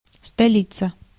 Ääntäminen
IPA : /mɪˈtɹɒpəlɪs/